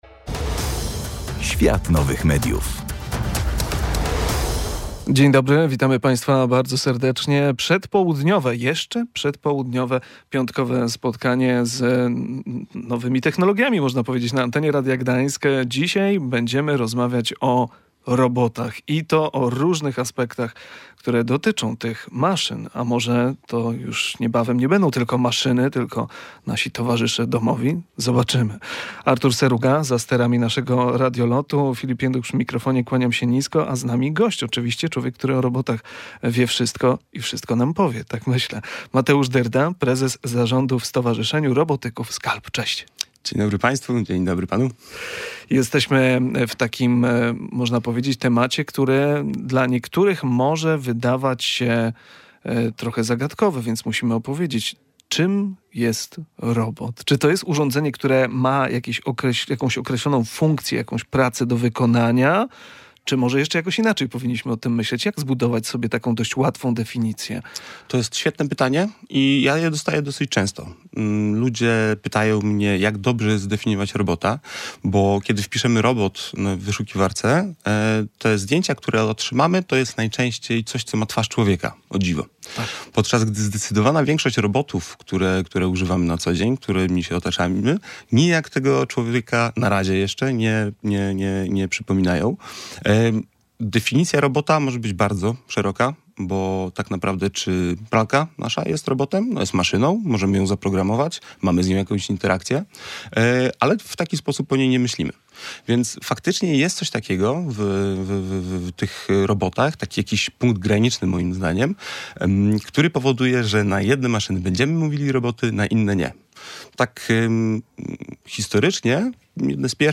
O wielu aspektach dotyczących tych maszyn opowiedział nam w kolejnym spotkaniu w audycji „Świat Nowych Mediów”